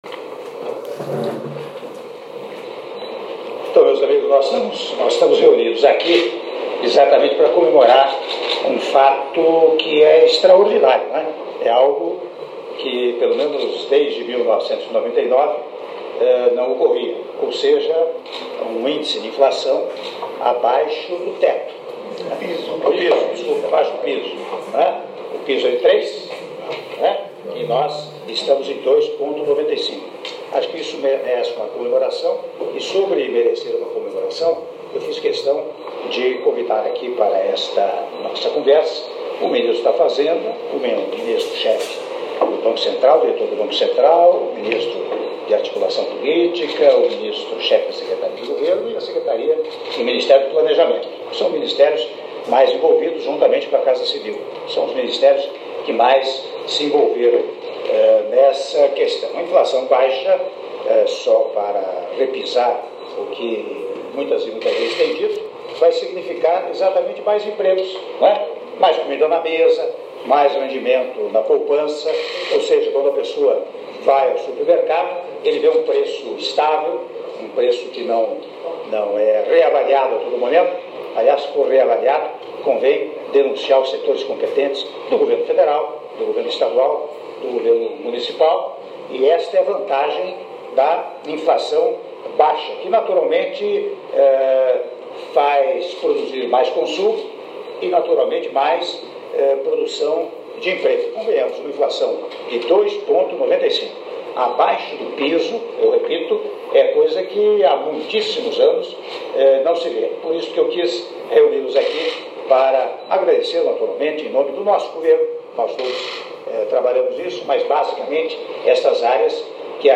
Áudio das palavras do Presidente da República, Michel Temer, na abertura da reunião com ministros e equipe da área econômica - (03min03s) - Brasília/DF